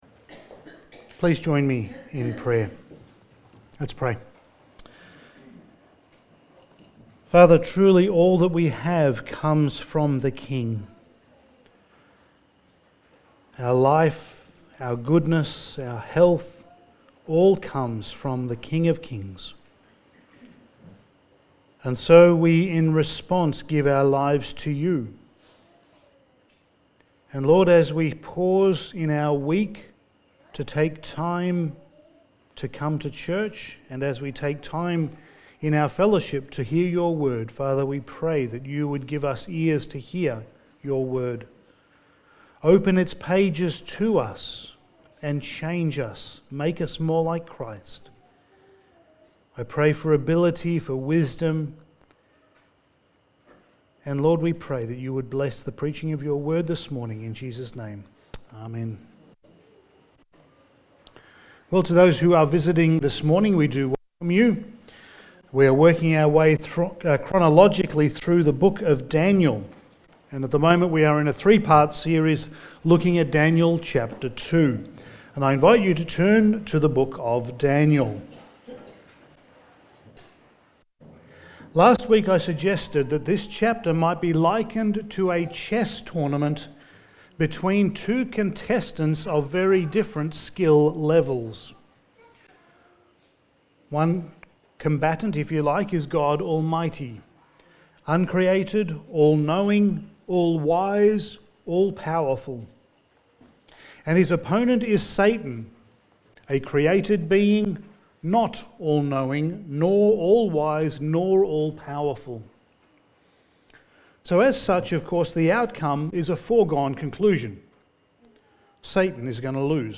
Passage: Daniel 2:17-30 Service Type: Sunday Morning